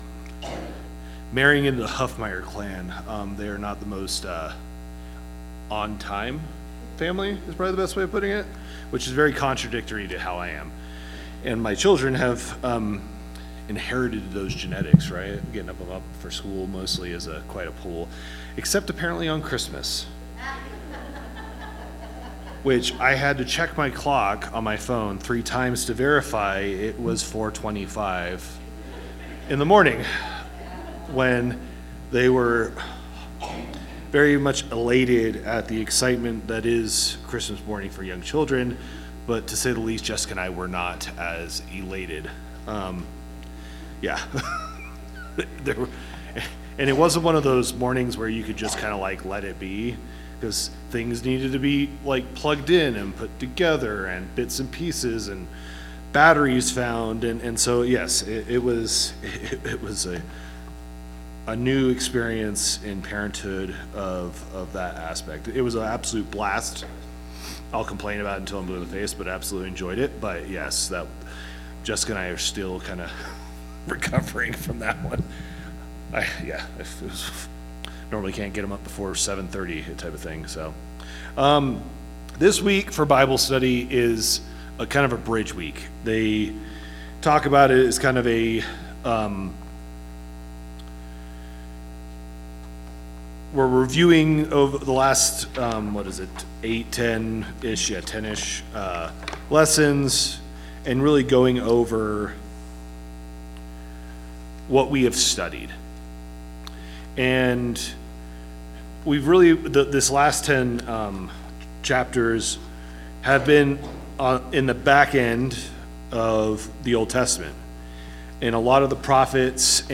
Bible Class 12/28/2025 - Bayfield church of Christ
Sunday AM Bible Class